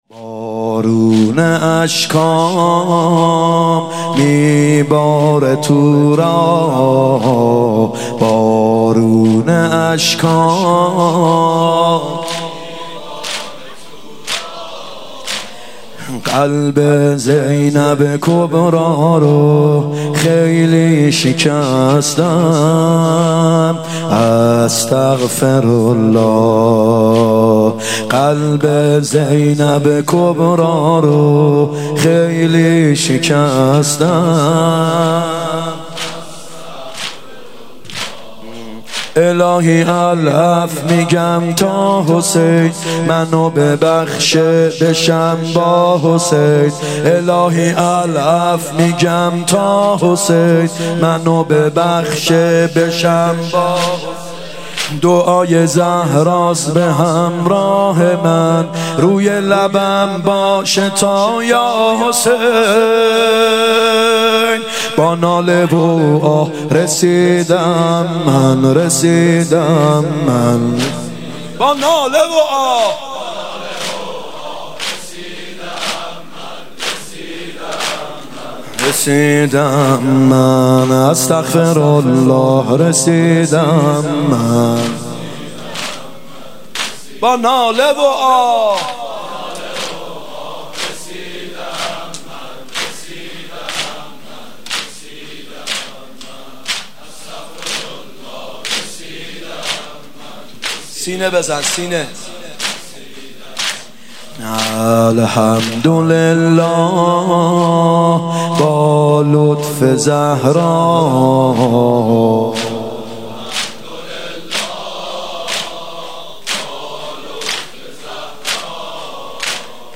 محرم 96 ( هیات یامهدی عج)